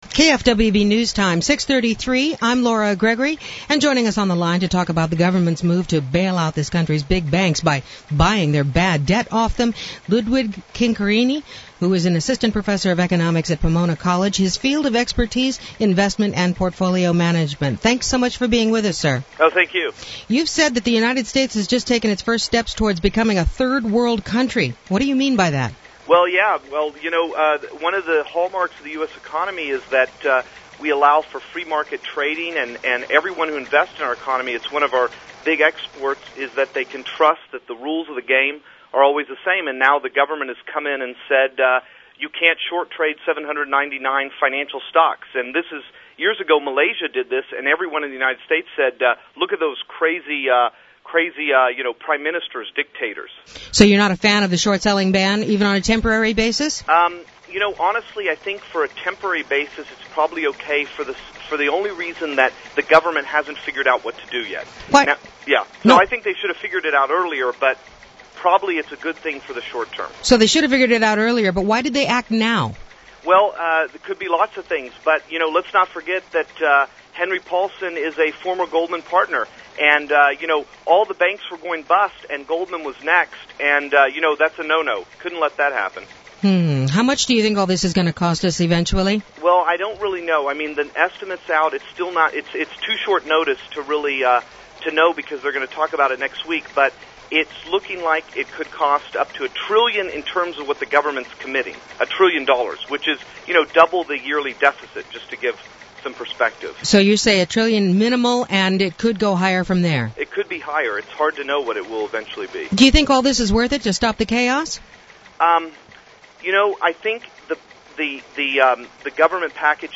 KFWB Radio Interview on U.S. Bailout of Financial System